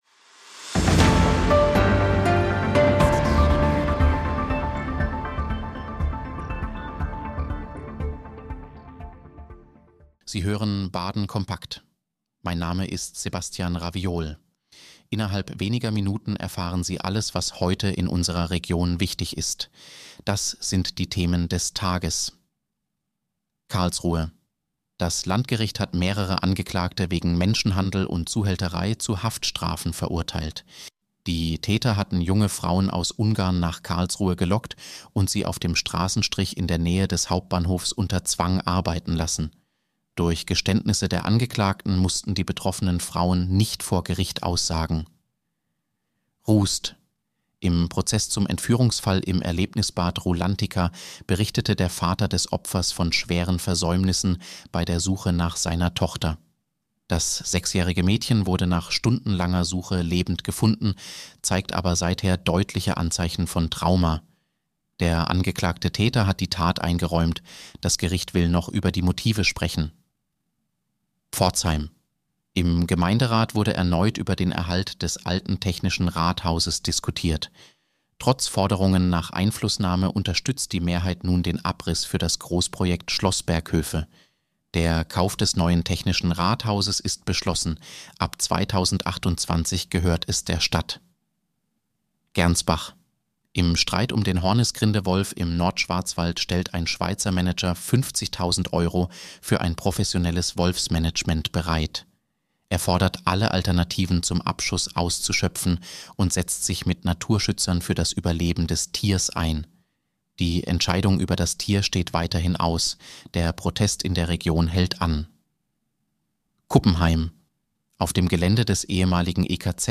Nachrichtenüberblick Mittwoch, 11. Februar 2026